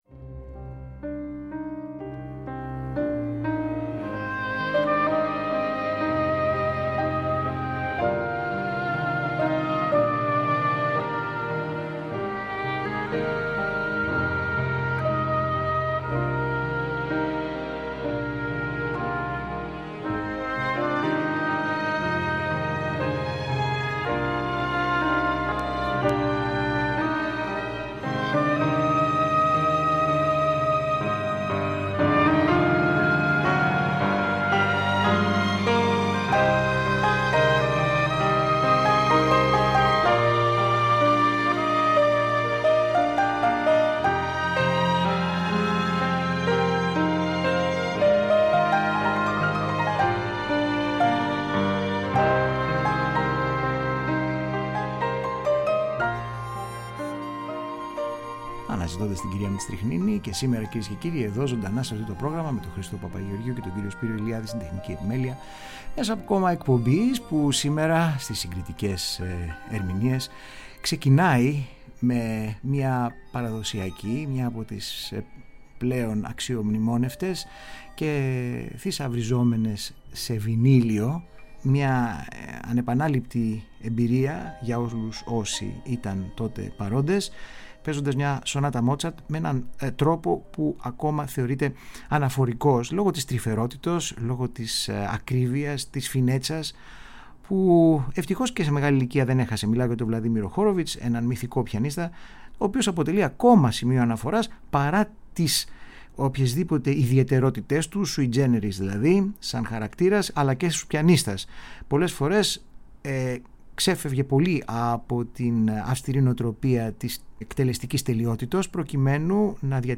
Τζαζ, ροκ, χορευτικές και δεξιοτεχνικές μεταμορφώσεις που αλλάζουν «δροστικά» και απρόσμενα το τοπίο της παραδοσιακής αντίληψης πασίγνωστων έργων.